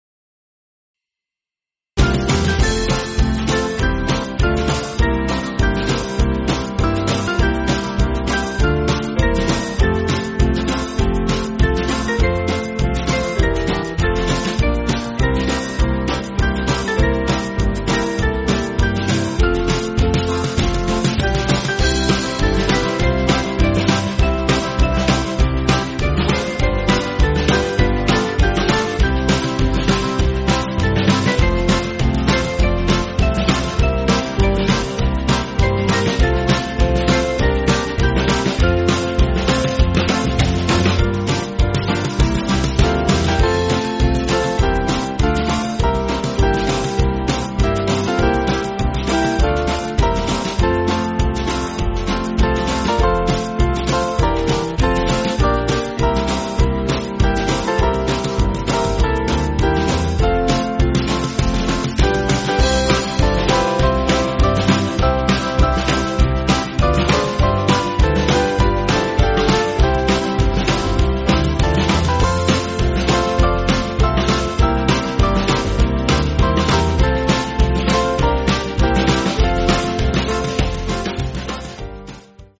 8.7.8.7.D
Small Band